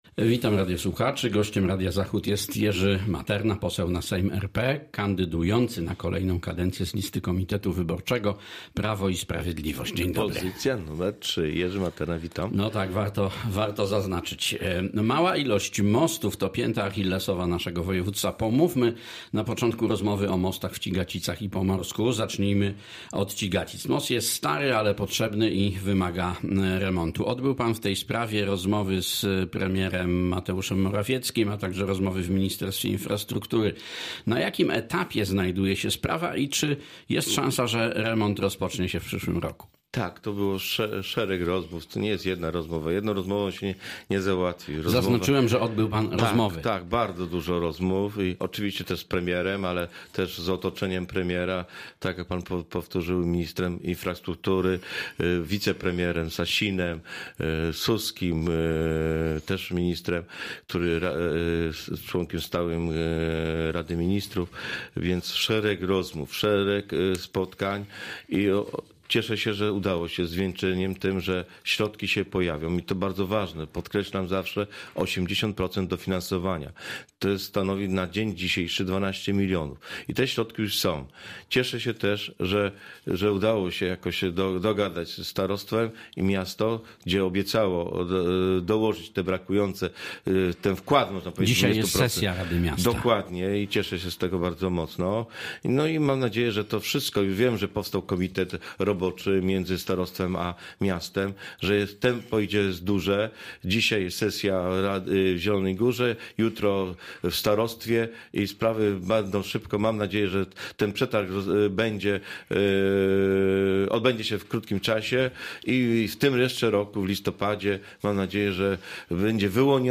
Z posłem PiS, kandydatem na posła do sejmu w nadchodzących wyborach rozmawia